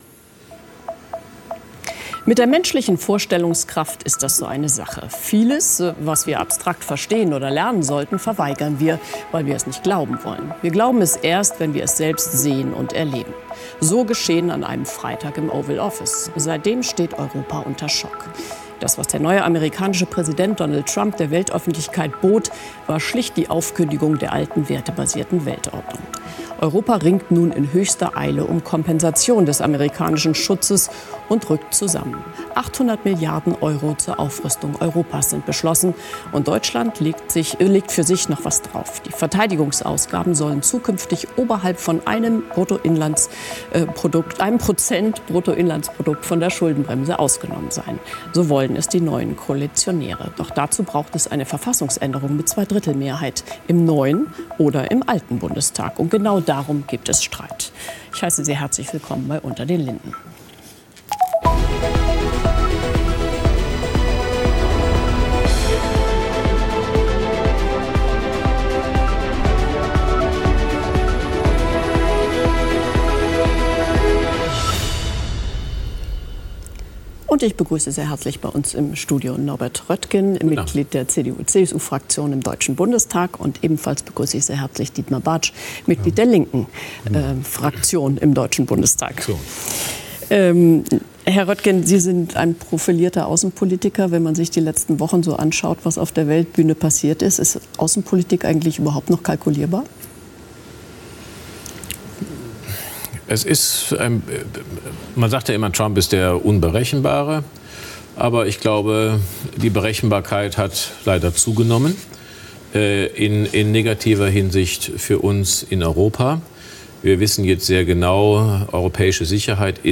„unter den linden“ ist das politische Streitgespräch bei phoenix.
Die Diskussionen sind kontrovers, aber immer sachlich und mit ausreichend Zeit für jedes Argument.